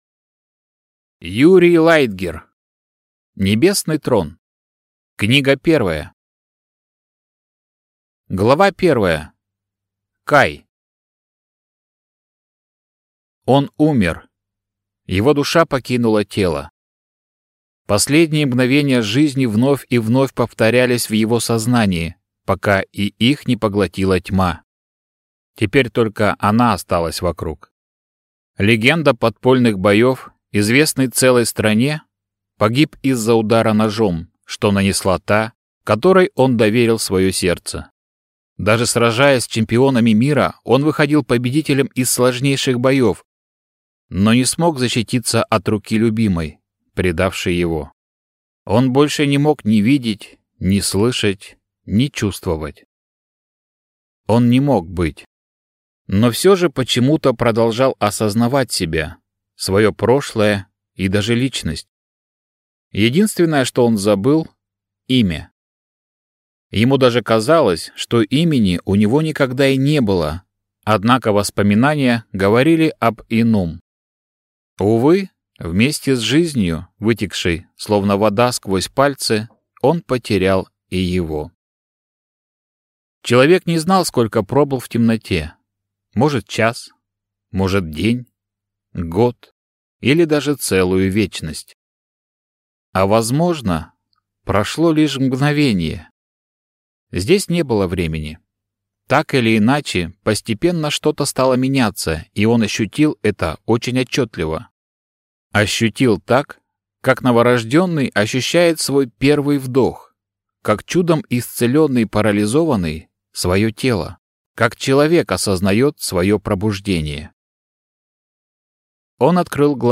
Аудиокнига Небесный Трон. Книга 1 | Библиотека аудиокниг